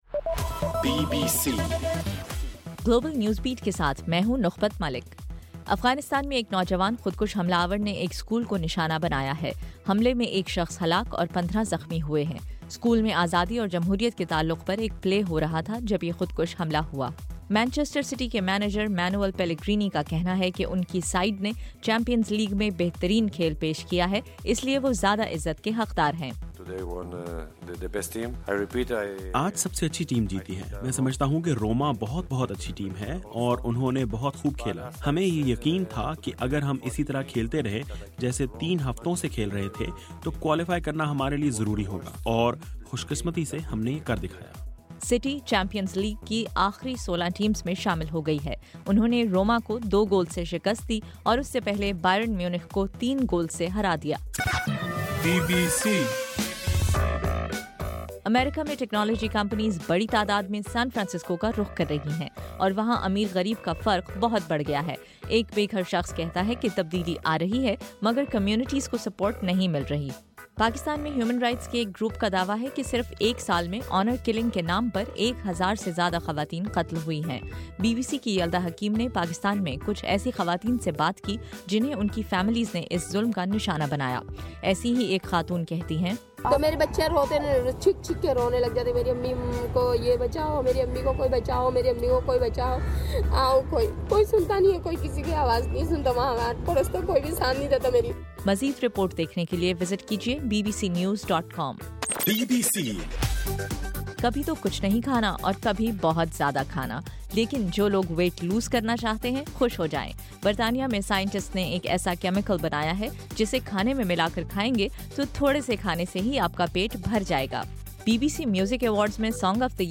دسمبر 11: رات 11 بجے کا گلوبل نیوز بیٹ بُلیٹن